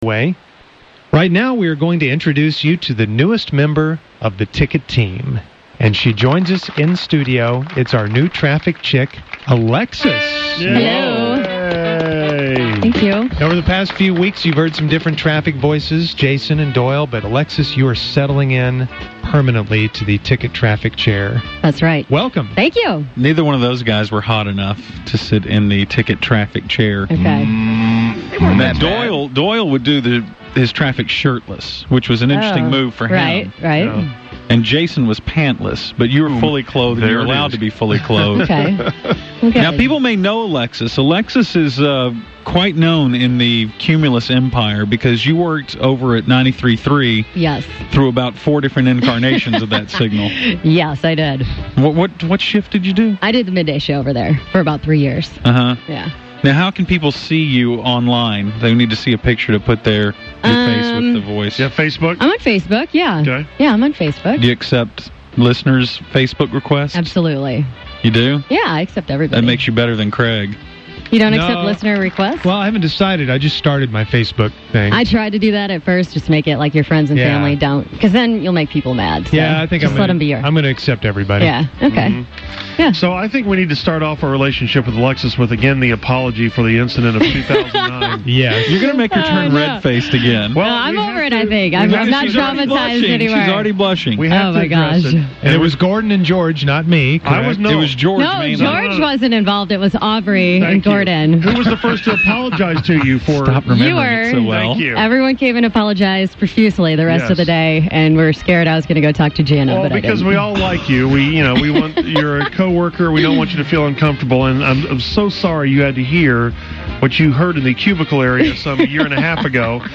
Musers play a little “get to know”, even though they’ve had her on before when she worked at the Bone.